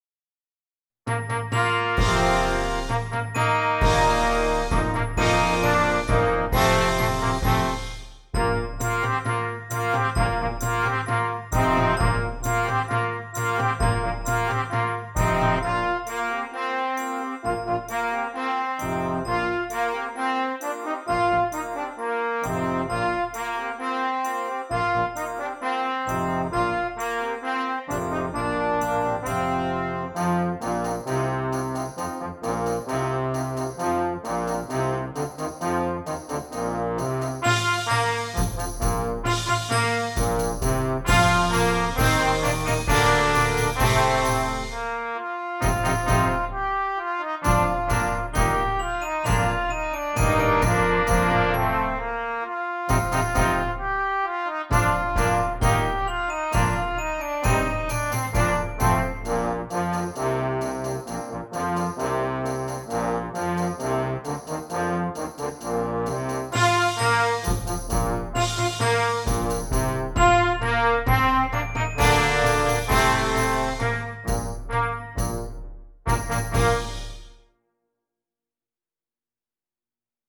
Brass Choir